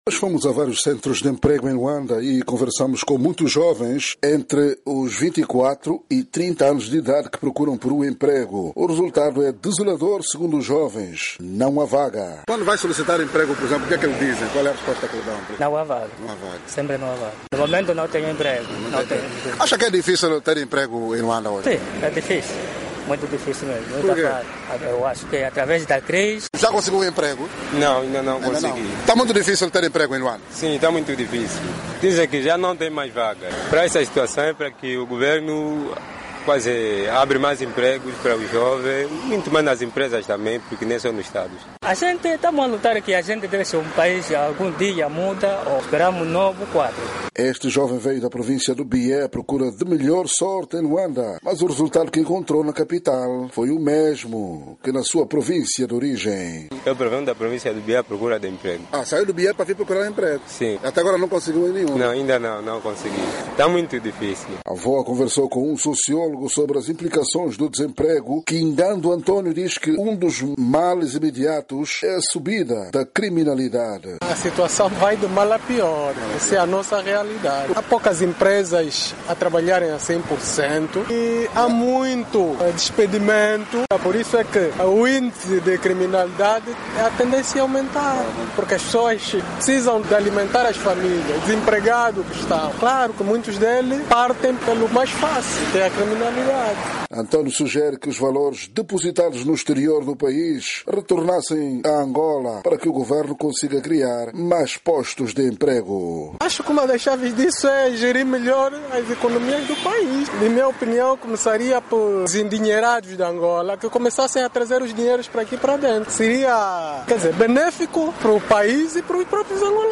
A VOA visitou vários centros de emprego em Luanda e jovens dos 24 aos 30 anos de idade relatam um “resultado desolador, não há vaga”.